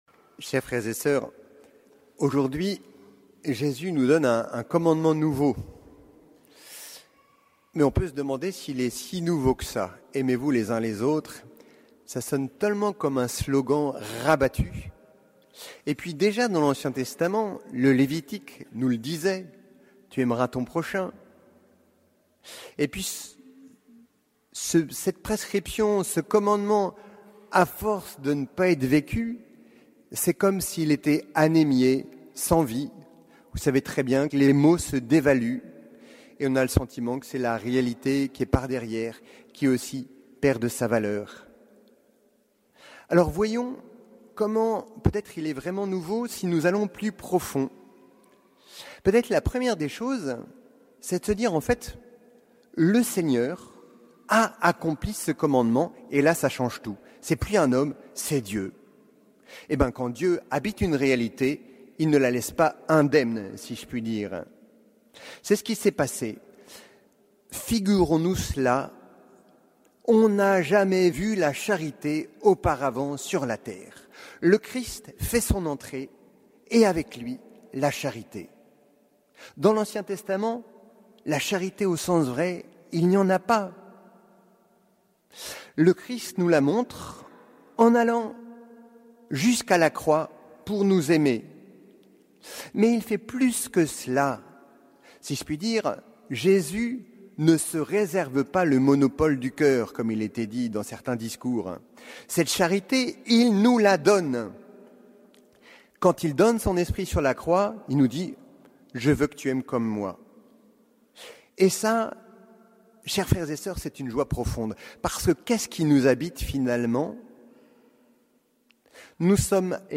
Homélie du cinquième dimanche de Pâques